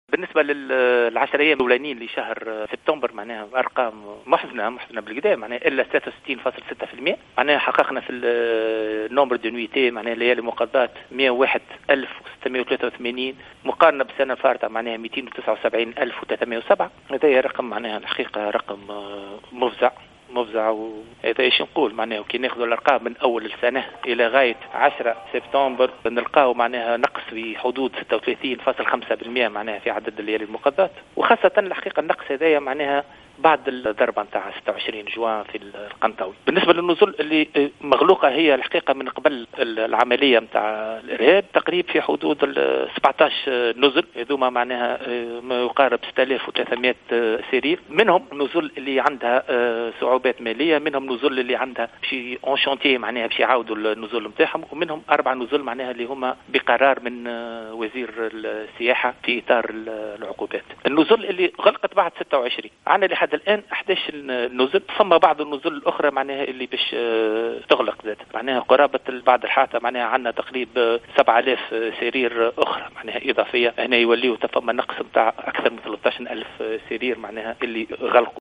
أكد المندوب الجهوي للسياحة بسوسة، فؤاد الواد اليوم في تصريح ل"الجوهرة أف أم" أن 11 نزلا قد أغلقت أبوابها في الجهة إثر هجوم سوسة الإرهابي الذي استهدف نزلا بالقنطاوي.